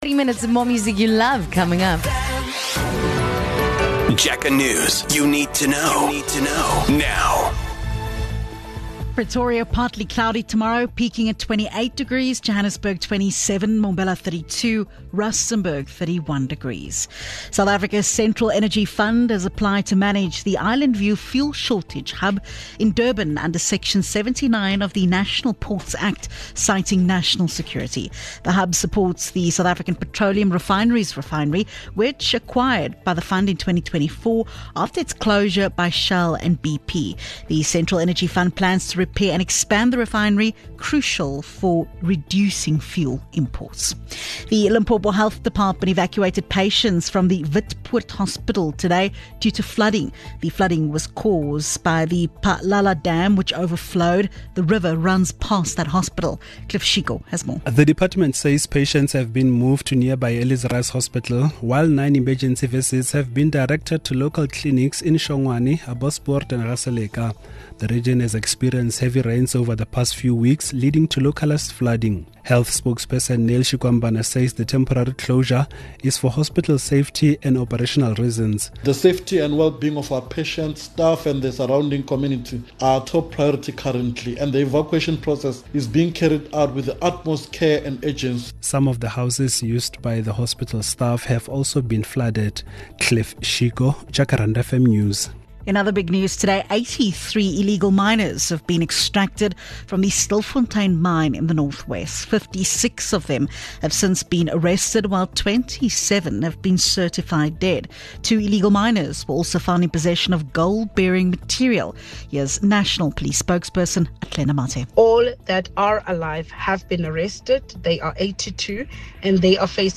Here's your latest Jacaranda FM News bulletin.